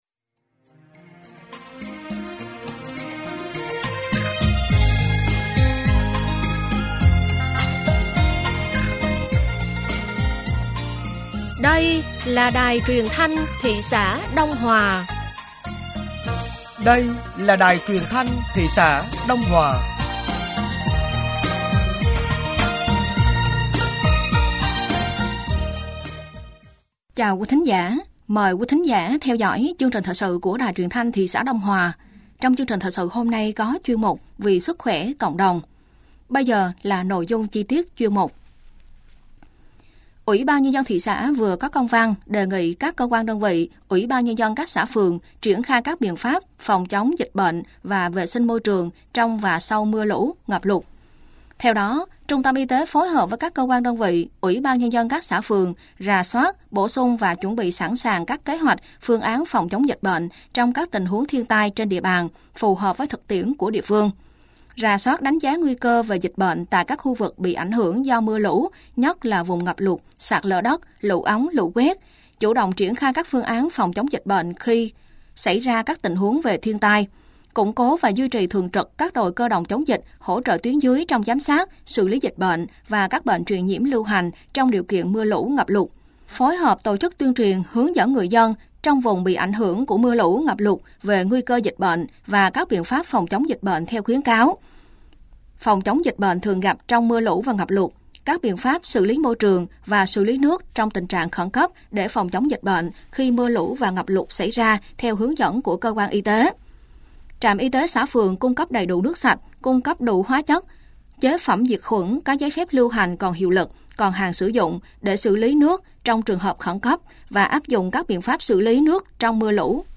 Thời sự tối ngày 29 và sáng ngày 30 tháng 9 năm 2024